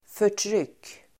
Uttal: [för_tr'yk:]